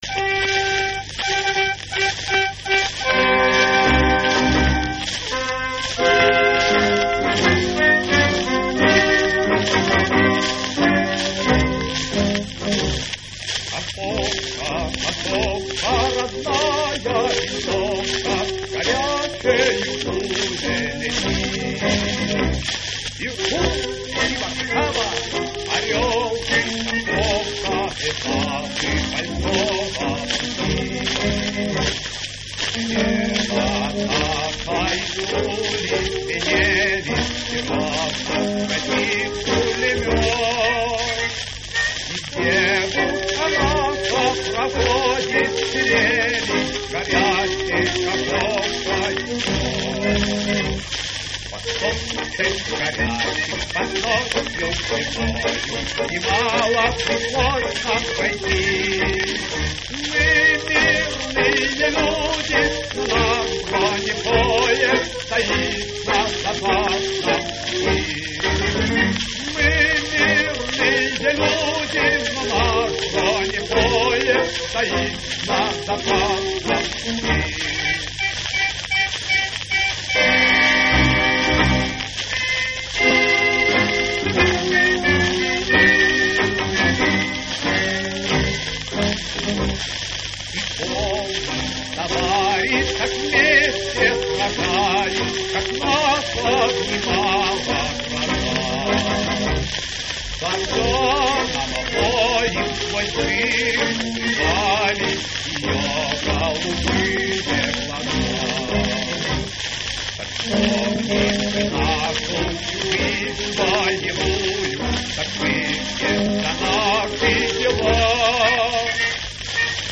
Самая ранняя запись песни на пластинку.